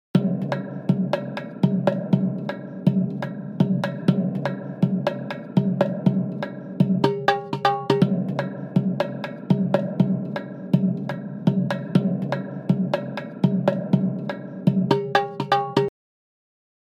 今回は、楽曲にエスニックな雰囲気を加えたいので、「ワールド」を選択します。
今回は、派手なものよりも落ち着いたニュアンスのループが欲しいので、「暗い」を選択します。
今回は、2小節ごとのカットに加え、4小節目と8小節目の後半をカットし、4小節ごとにフィルを取り入れました。
▶ループフレーズにフィルを加えた結果
Logic11-Appleloops-Fill.mp3